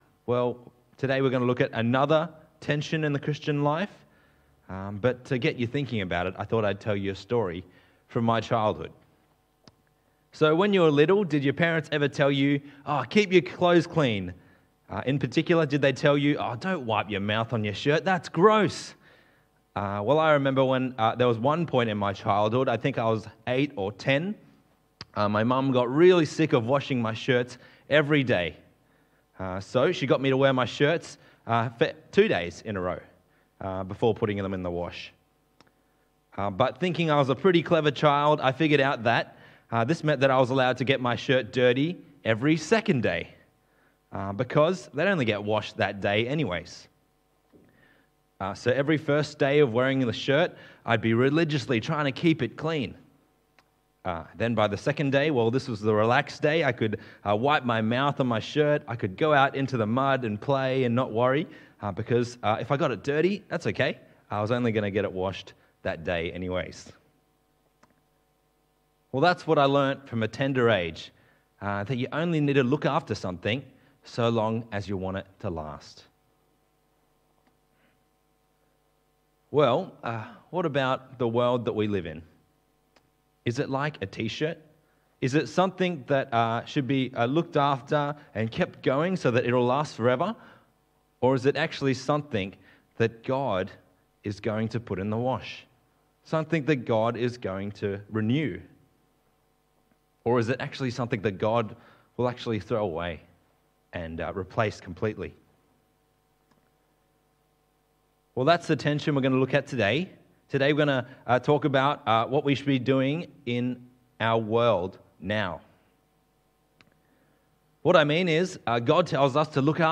Talk Type: Wintercon